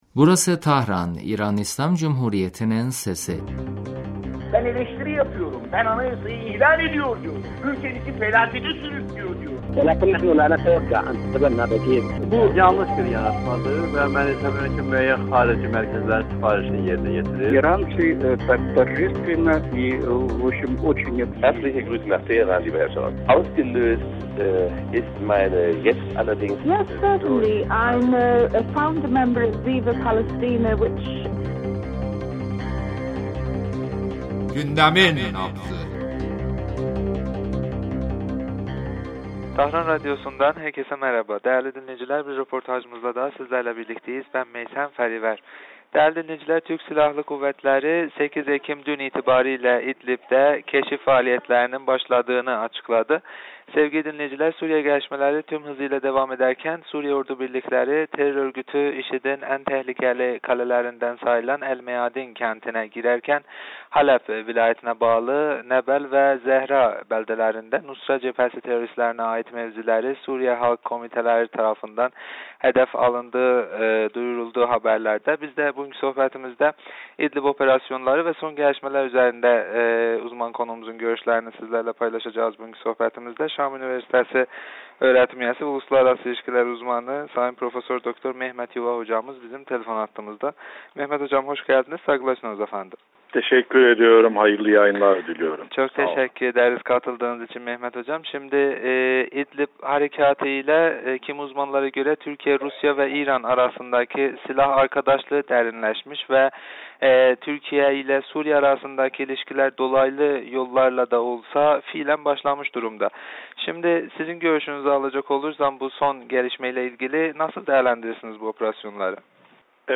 telefon görüşmesinde son dönem Suriye'de başlatılan idlib operasyonları üzerinde konuştuk.